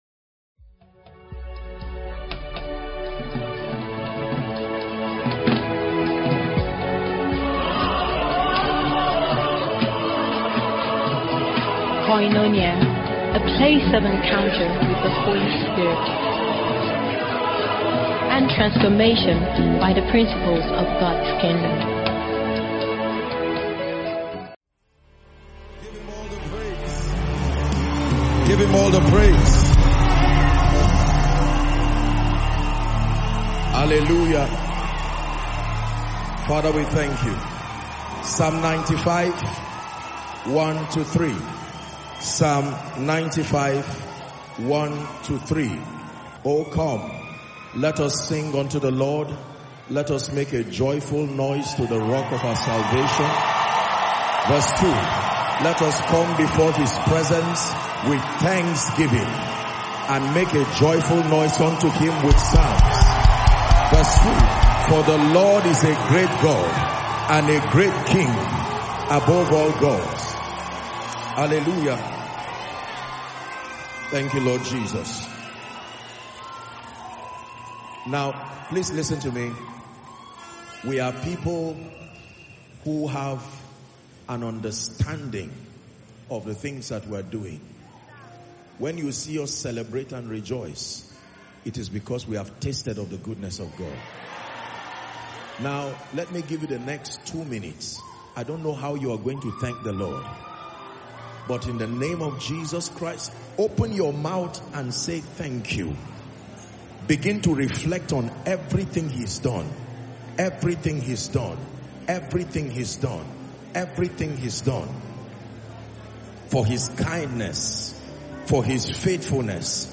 Sermon
This message, delivered at Koinonia on December 15, 2025, presents a structured framework of Kingdom mysteries that govern spiritual growth, stability, and influence.